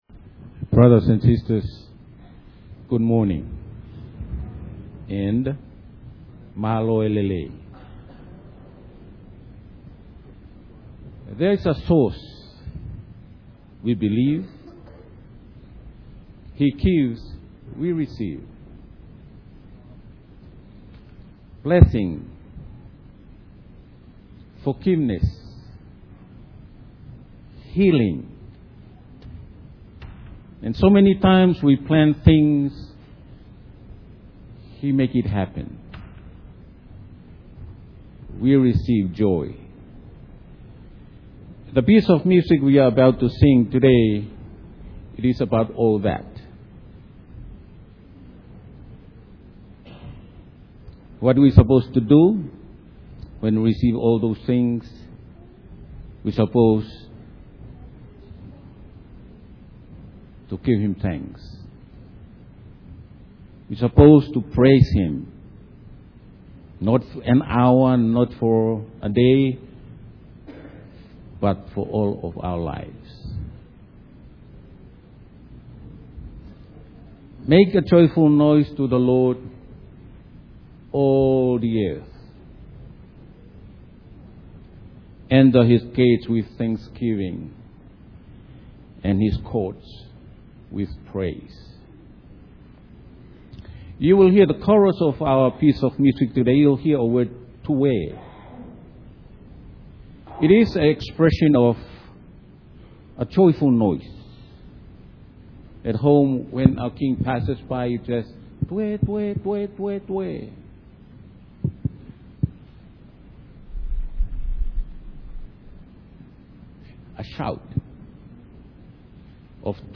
Pentecost Sunday Worship Service
Song                                                                                            Tonga Fellowship Choir
07TongaFellowshipChoir.mp3